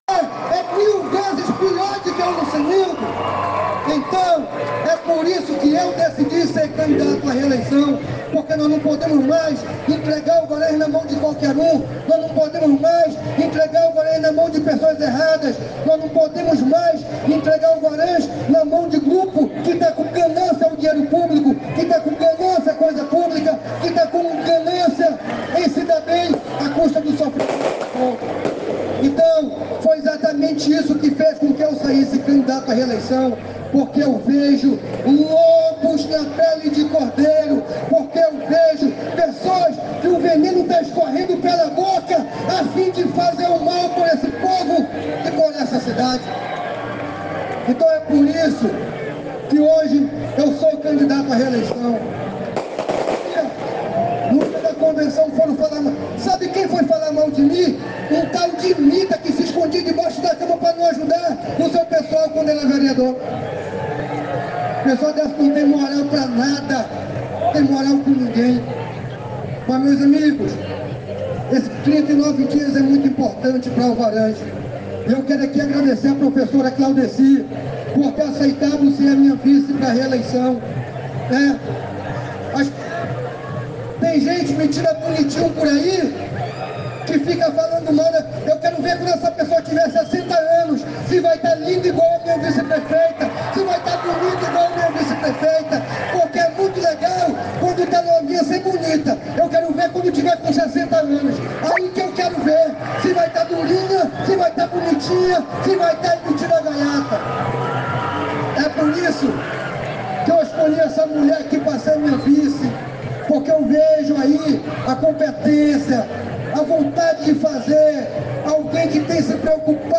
O evento, realizado em praça pública por volta das 20h00, gerou grande repercussão e revolta entre os participantes e a população local.
Ouça o áudio completo do discurso do prefeito Lucenildo durante o evento: